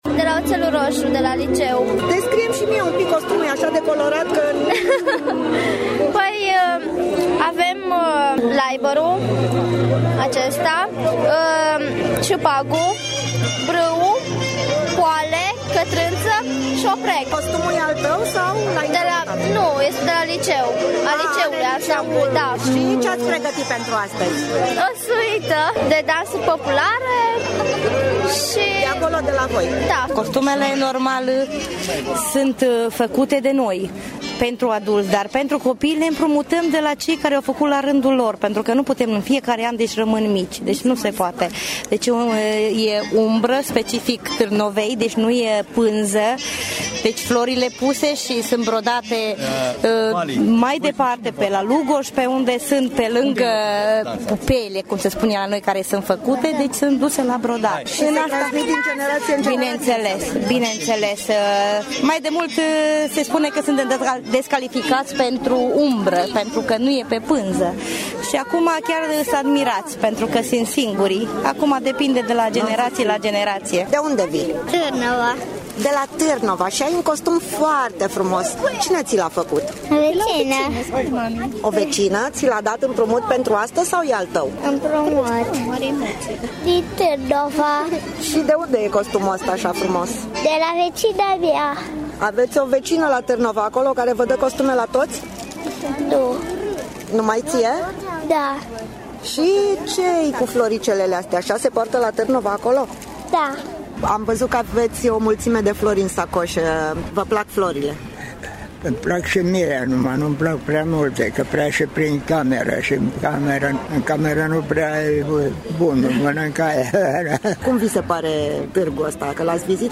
Dar în parcul Tricolorului se mai întâmplă ceva, după ce dansatori din tot judeţul au defilat în alai s-a adunat cu mic, cu mare în mijlocul parcului şi au început să danseze.
Dansatori din tot judeţul şi-au prezentat costumele populare